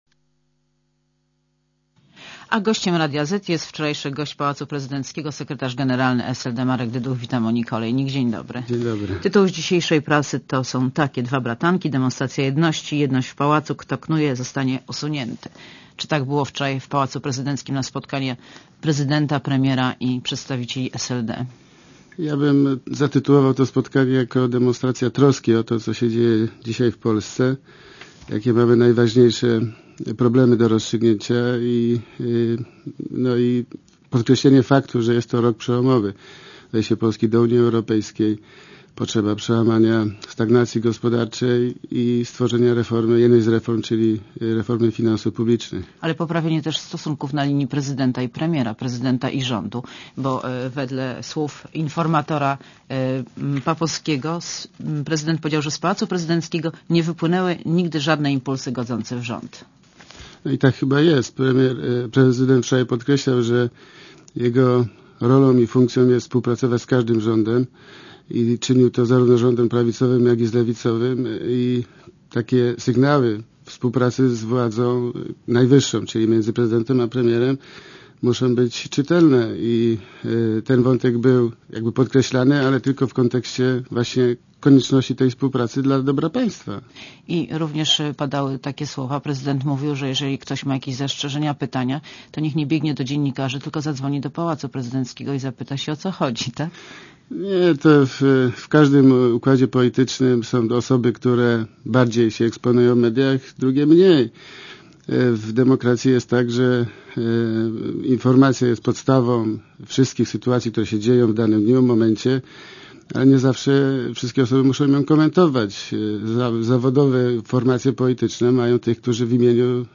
Monika Olejnik rozmawia z Markiem Dyduchem - sekretarzem generalnym SLD